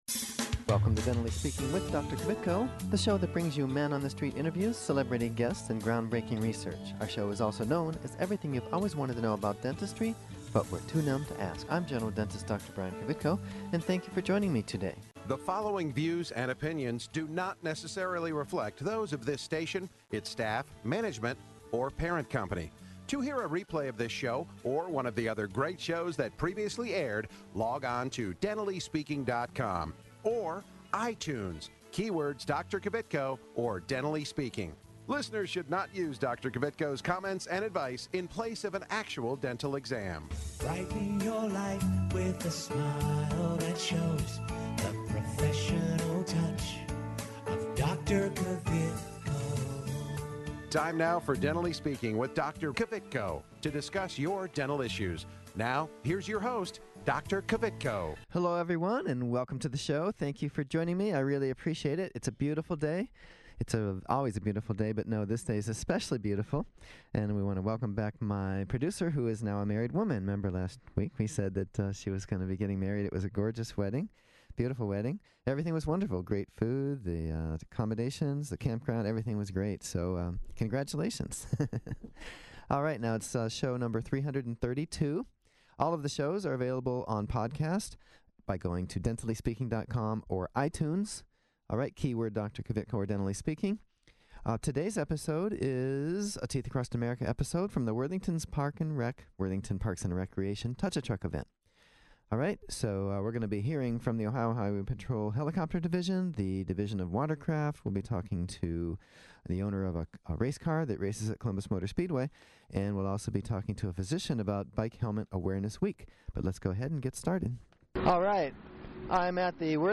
Teeth Across America” a visit to the Worthington Parks and Recreation Touch-A-Truck event